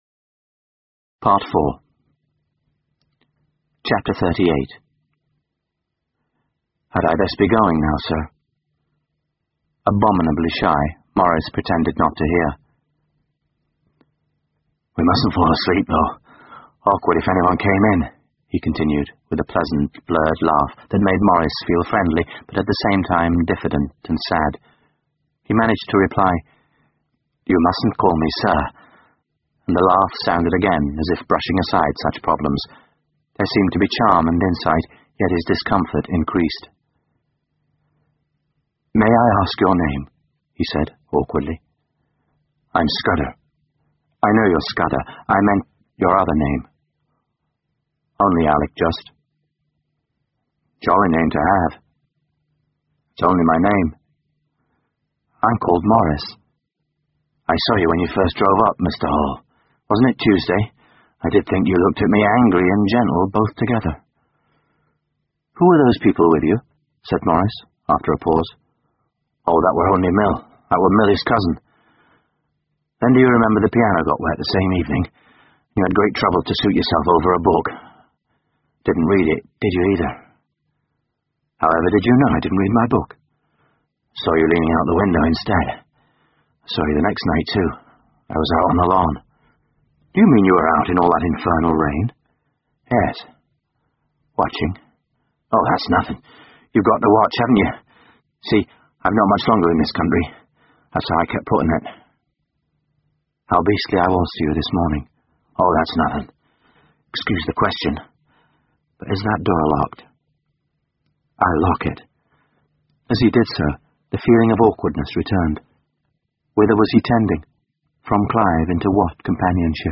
英文广播剧在线听 Maurice 莫瑞斯 - E. M. Forster 38 听力文件下载—在线英语听力室